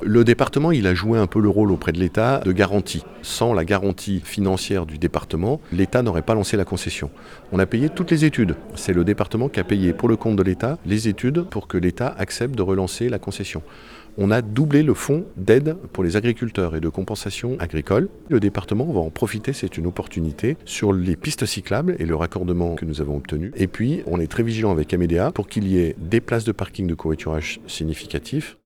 Le département qui a justement joué un rôle crucial dans le développement du projet et Martial Saddier précise même les différentes aides apportées en ce sens.